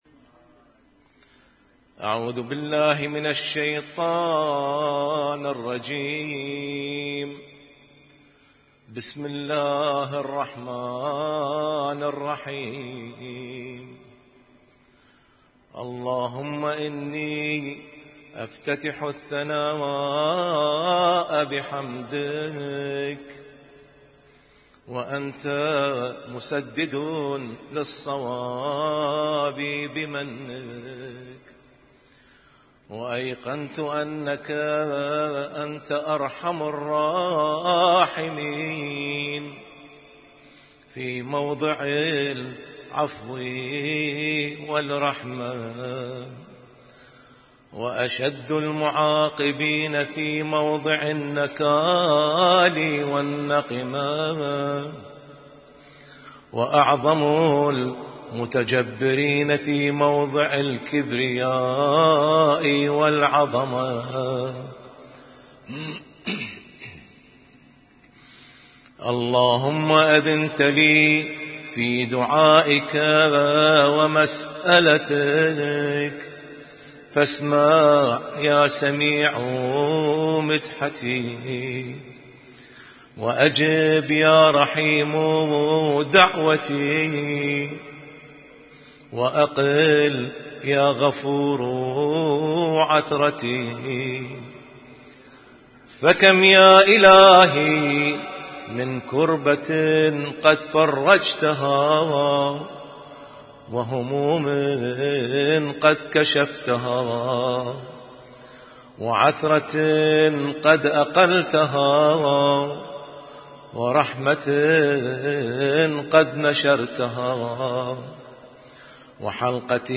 Husainyt Alnoor Rumaithiya Kuwait
اسم التصنيف: المـكتبة الصــوتيه >> الادعية >> دعاء الافتتاح